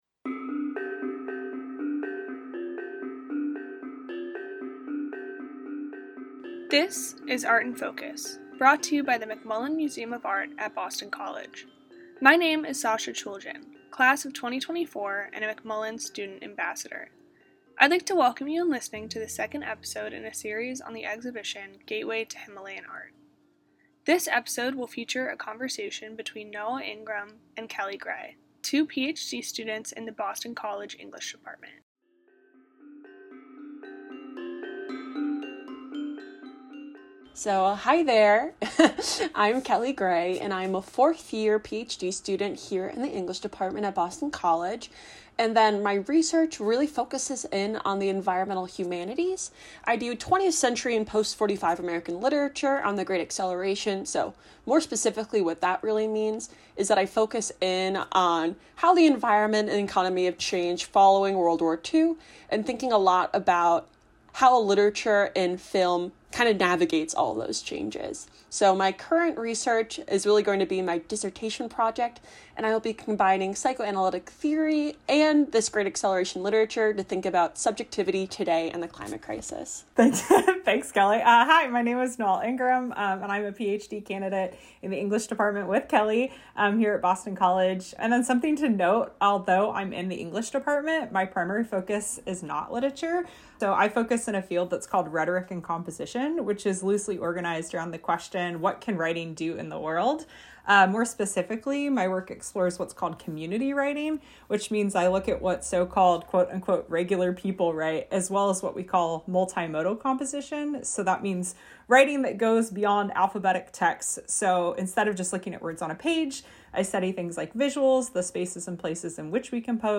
The McMullen Student Ambassadors are pleased to present Art in Focus, featuring an informal discussion between Boston College professors from various academic departments.